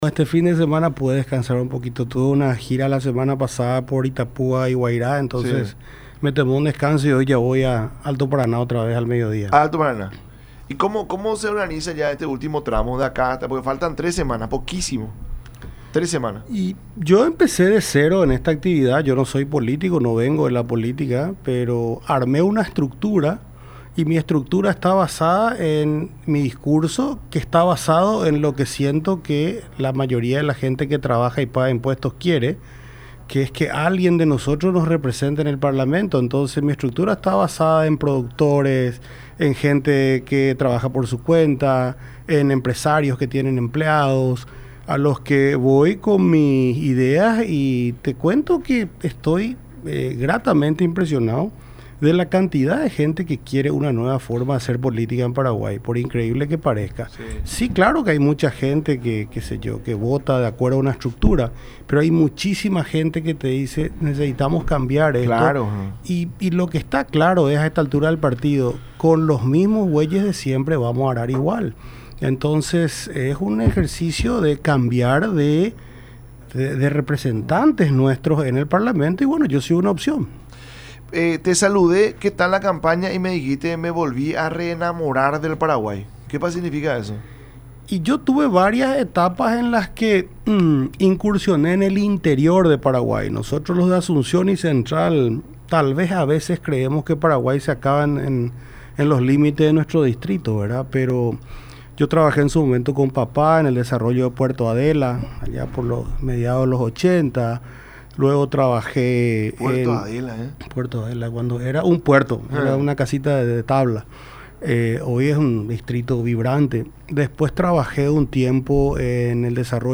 Aduanas tiene que recaudar por lo menos 25% a 30% más en dólares”, manifestó Leite en su visita a los estudios de radio La Unión y Unión TV durante el programa La Mañana De Unión.